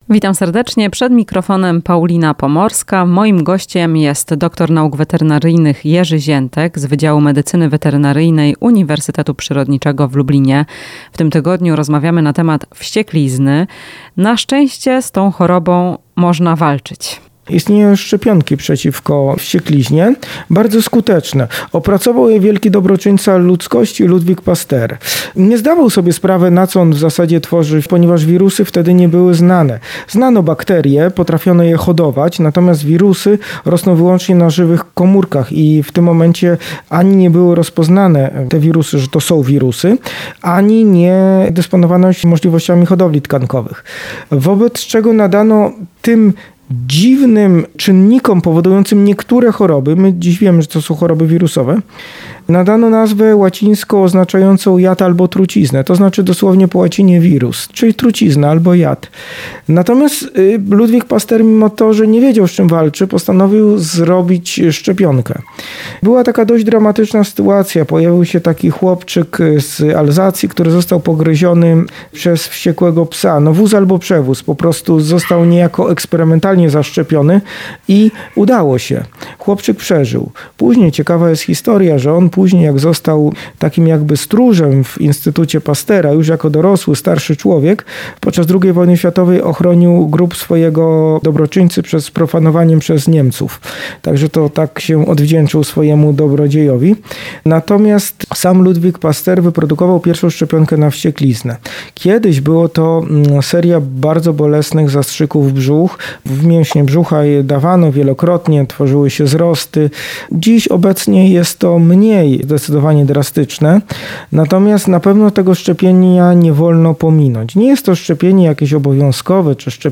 Rozmowa z dr. n. wet.